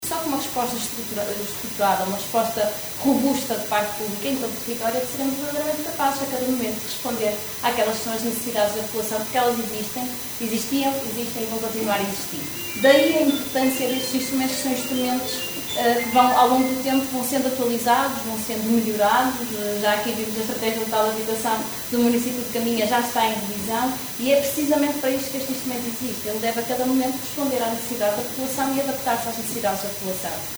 Terminadas as visitas, seguiu-se uma sessão protocolar na Biblioteca Municipal de Caminha, onde as várias entidades procederam às assinaturas dos contratos.
Marina Gonçalves, Ministra da Habitação, encerrou a cerimónia referindo o “sabor especial” que era estar em casa a pôr em prática uma política nacional no território.